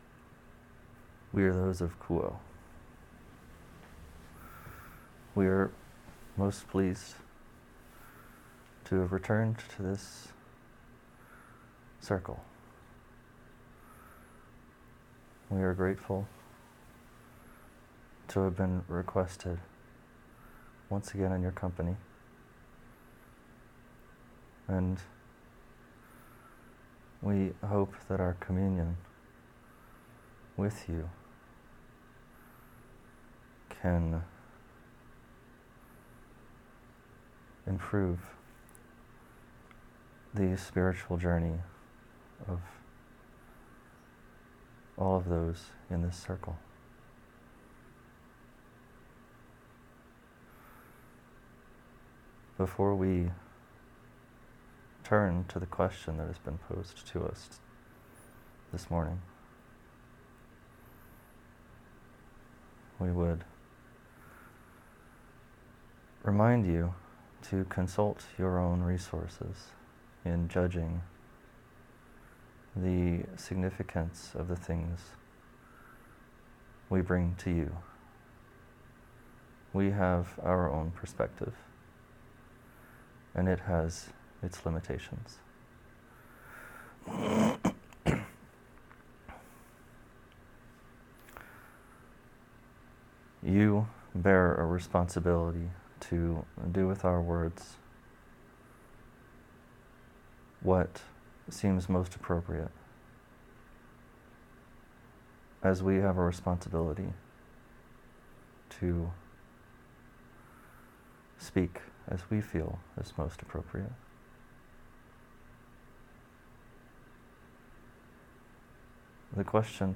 In this first session from the second OSWG channeling intensive, Q’uo offers details about the different kinds of entities one might channel and how to appropriately protect oneself in doing so. They begin by articulating the inner planes/outer planes distinction and why it makes a difference in channeling.